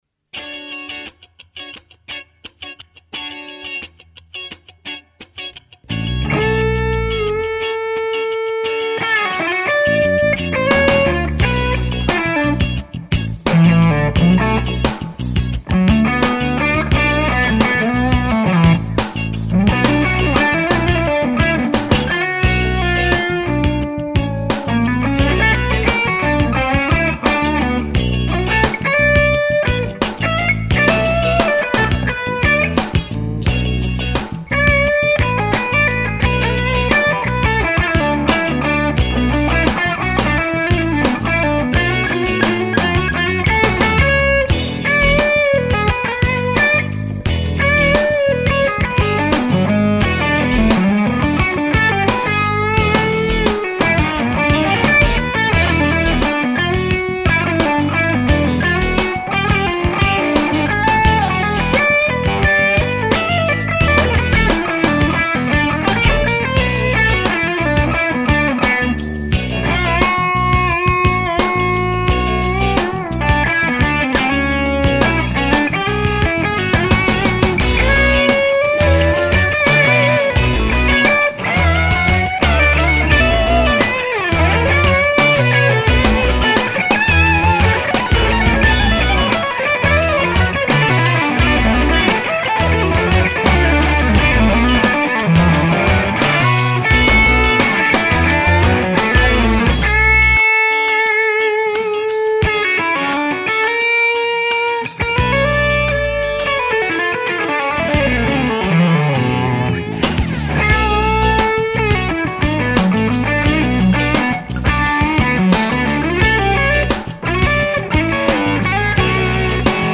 Fuchs SLX50 amp and Hermida Z Cab. Thiele port with G1265 speaker. Royer R121 about 3 feet back and aimed between ports and speaker surround.
The take was my first and only take and I was not expecting the unison heavy guitar part.
Amp is 80s circuit. Recorded dry. Volume on 3 so not too loud. Gain on 1 oclock and OD gain on 11 oclock. Treb 10 oclock, mids 1 oclock, bass 1 oclock.
I thought the pick attack was a little harsh sounding, and the balance of the tone a little thin compared to your other amps.
The treble thing is probably the mix of Royer and the Thiele port cab.
Recording is just R121 into mic pre into Adobe Audition.